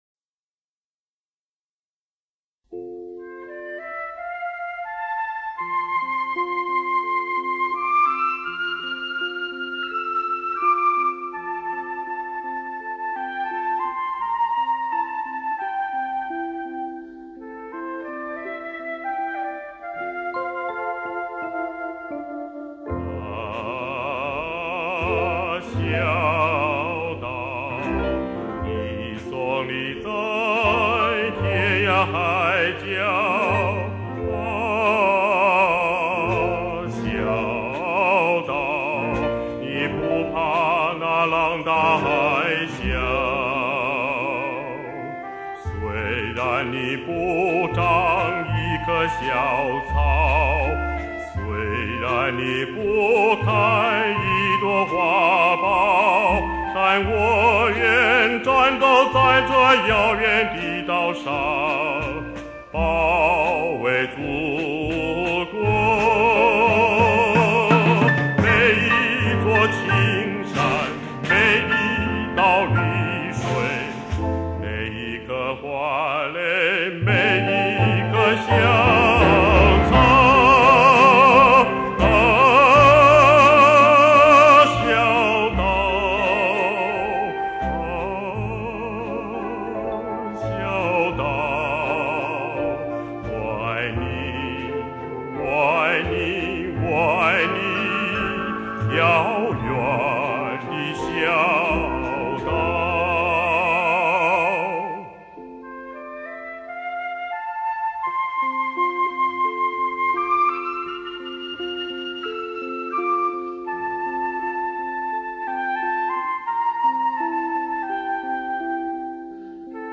乐队中一点点电声和适度的架子鼓点缀并不喧宾夺主。